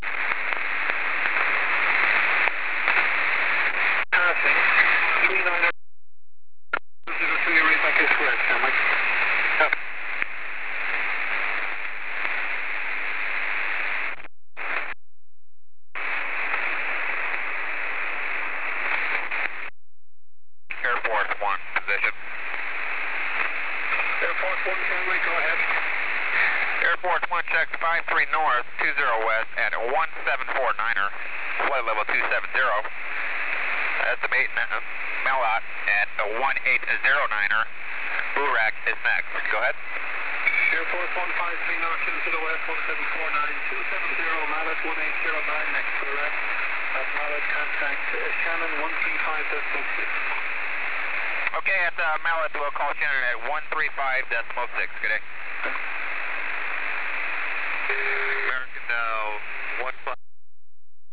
airforce one hf audio talking to ireland